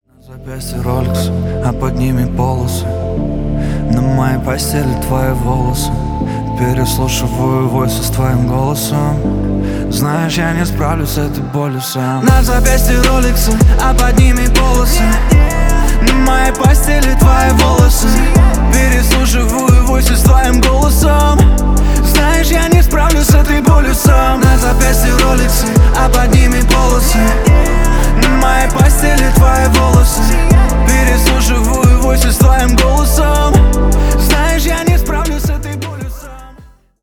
Поп Музыка
спокойные